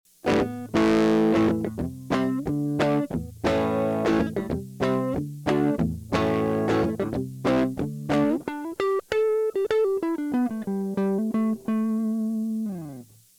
Samples were made using a Fender Stratocaster with Lollar S Series single coil pickups and a Fender Concert amp. Neck pickup selected with volume and tone controls at maximum. Standard tuning. The amp tone controls are set to 12 o’clock with the reverb turned off.
Treble Boost
A low gain sound with the Treble and Level set higher to provide a boost.